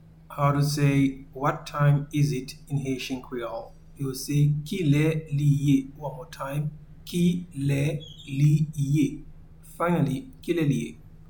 Pronunciation and Transcript:
What-time-is-it-in-Haitian-Creole-Ki-le-li-ye.mp3